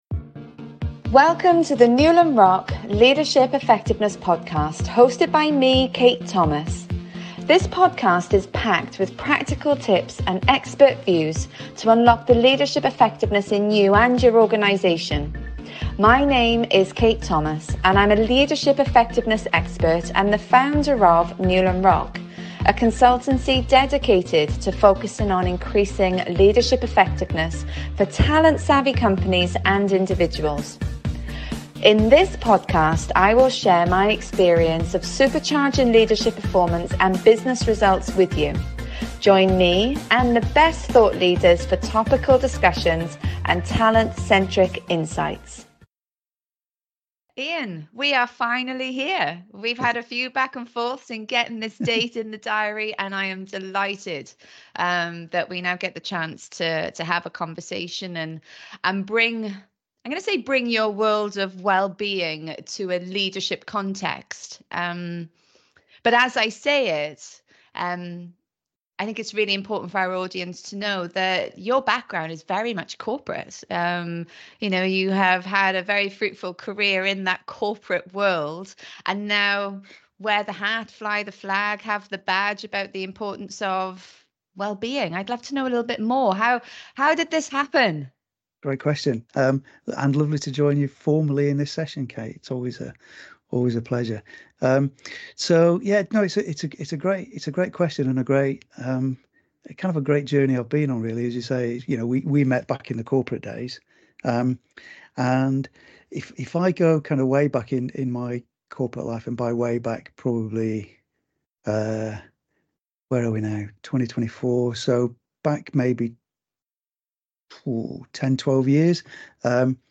I interview